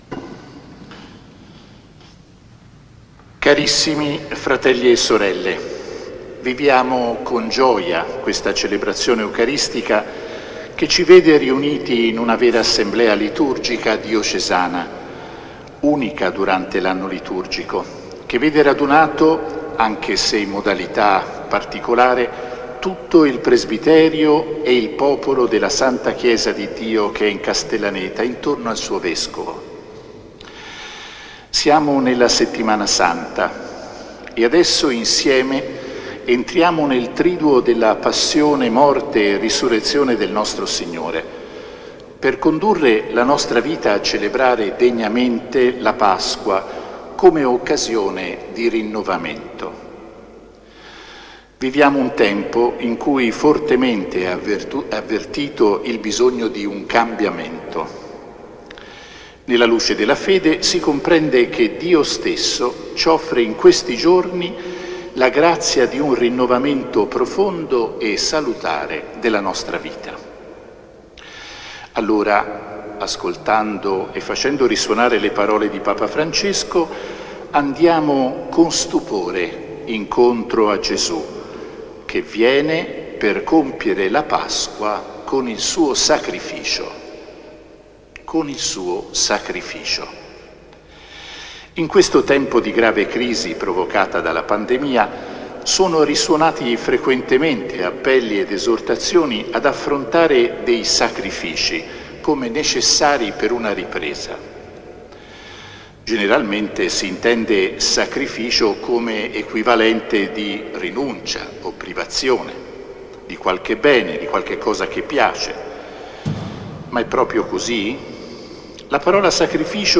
Omelia del Vescovo nella Santa Messa Crismale
L'omelia di Mons. Claudio Maniago pronunciata nella Santa Messa Crismale 2021 presso la Parrocchia del Cuore Immacolato di Maria.